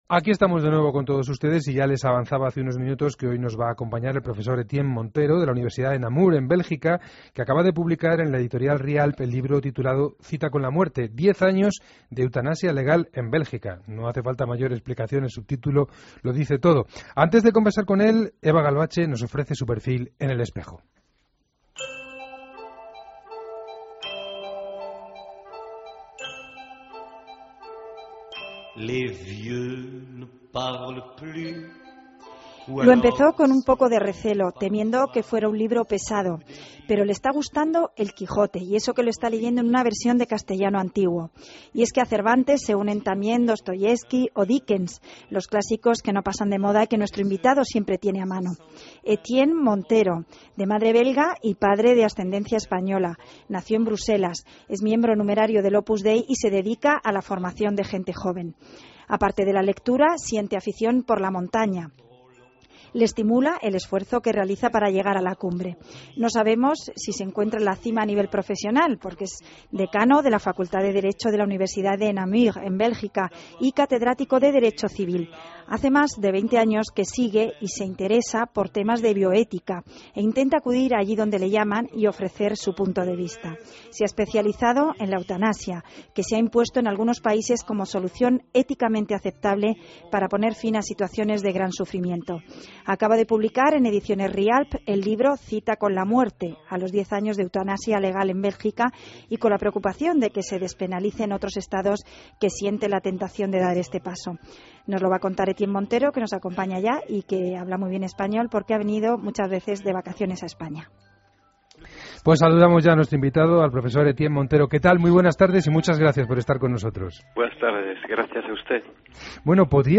AUDIO: Escucha la entrevista en El Espejo